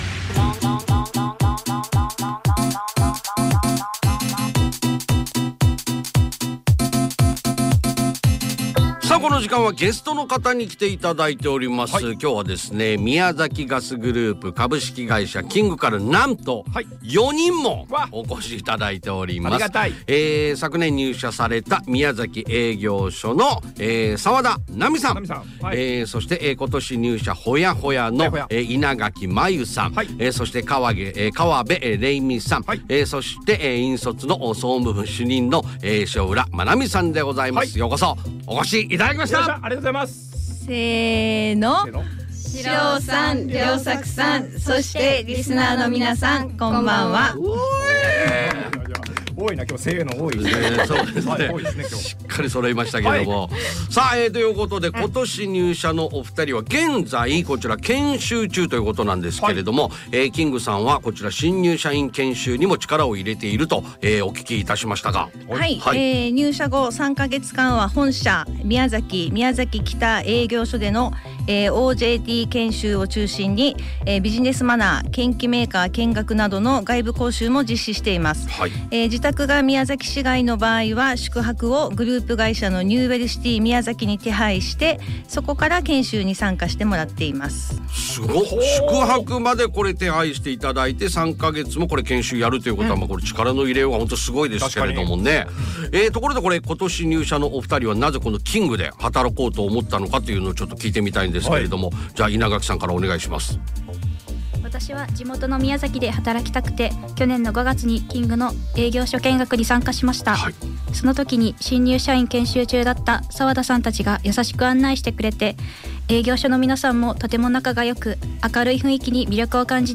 令和7年4月24日木曜日に放送された、エフエム宮崎「Radio Paradise 耳が恋した！」で企業紹介が放送されました。